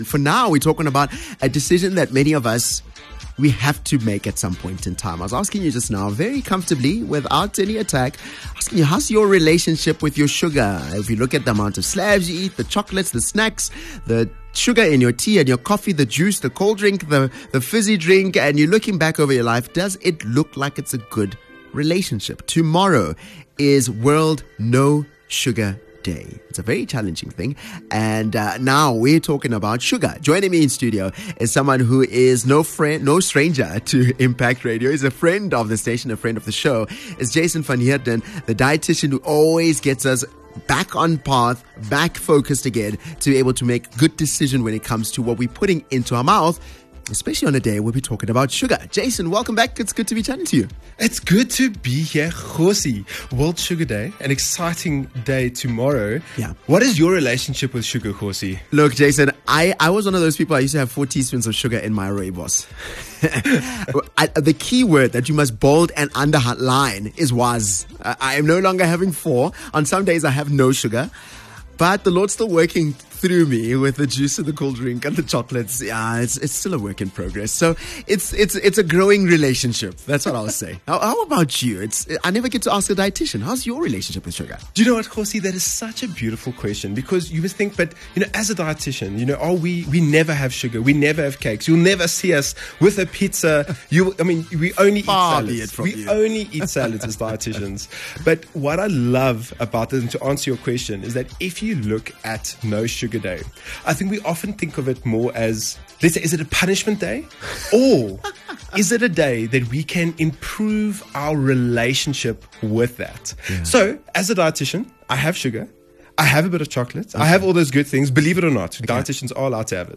Discover insights on how sugar affects our health, tips for moderation, and ways to make informed choices for a balanced lifestyle. Don’t miss this enlightening conversation!